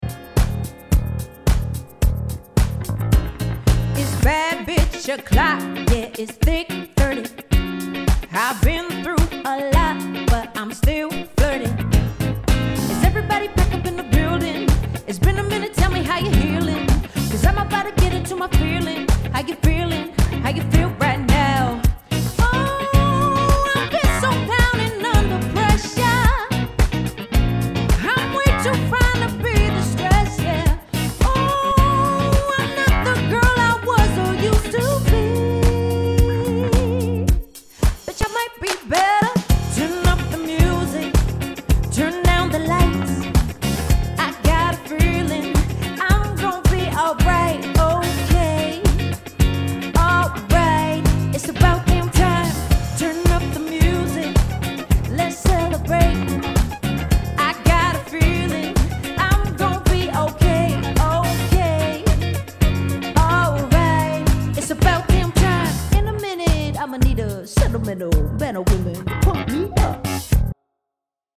Soul, Rock und Pop
LIVE COVER